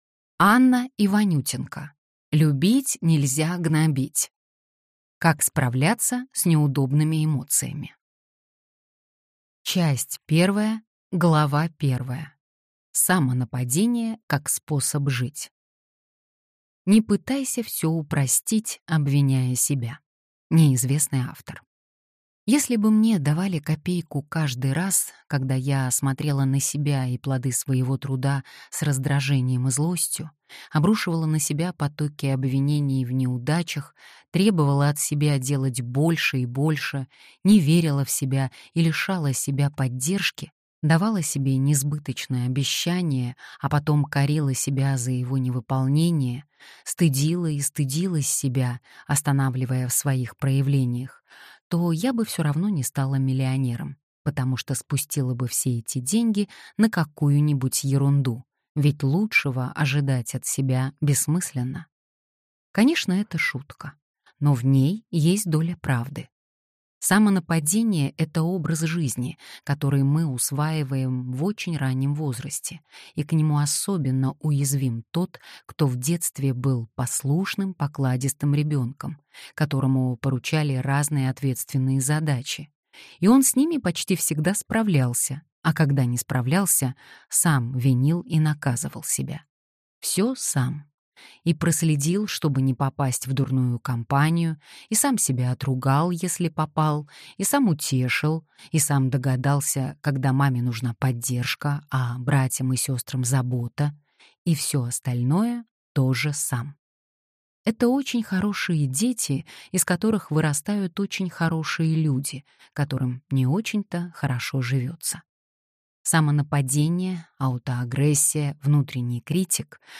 Аудиокнига Любить нельзя гнобить. Как справляться с неудобными эмоциями | Библиотека аудиокниг